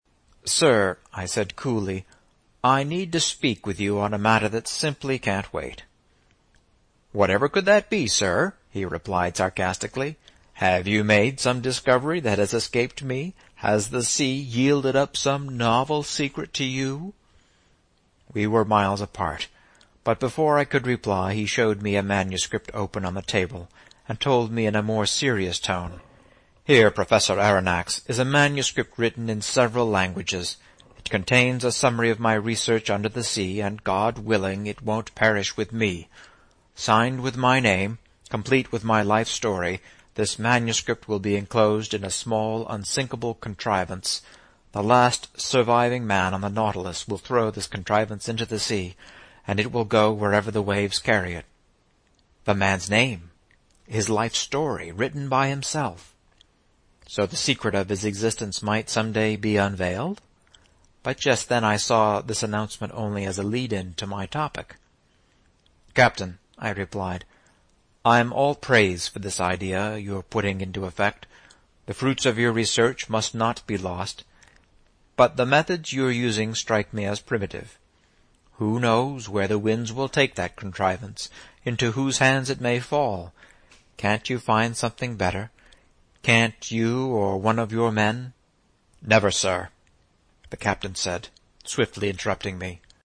在线英语听力室英语听书《海底两万里》第519期 第32章 海湾暖流(10)的听力文件下载,《海底两万里》中英双语有声读物附MP3下载